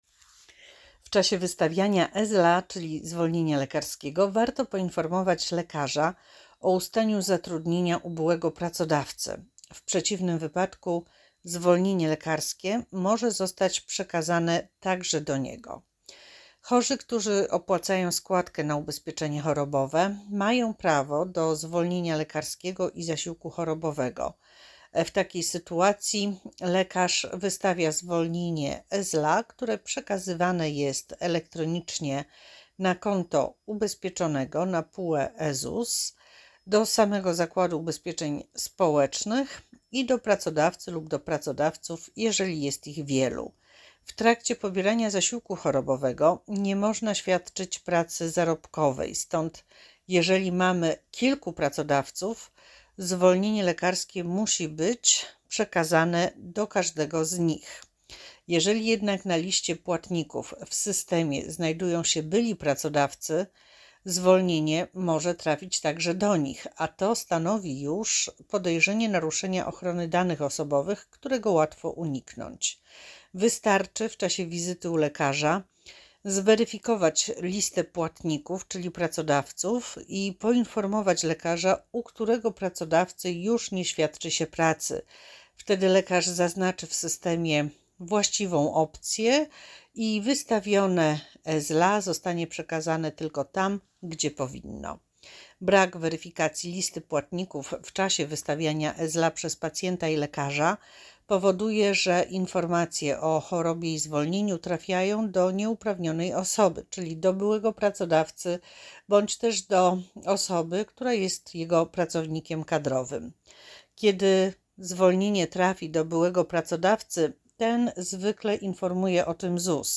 audiodeskrypcja_170.mp3